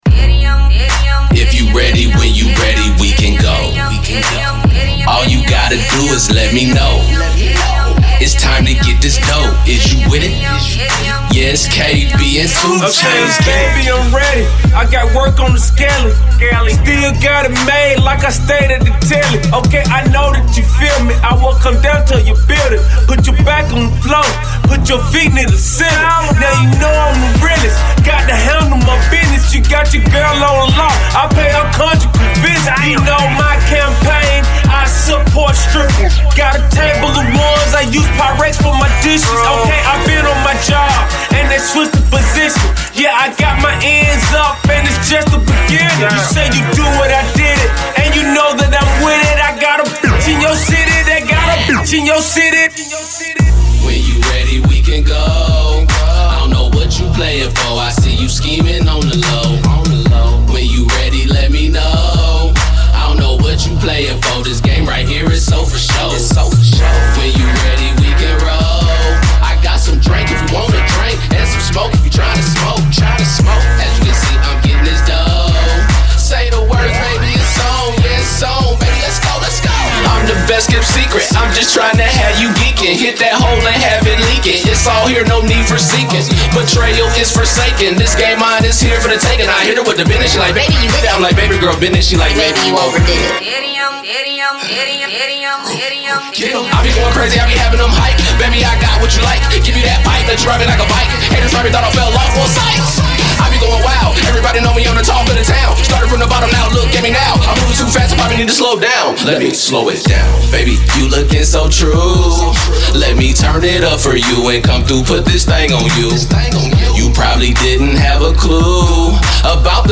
Hiphop
This is a banger.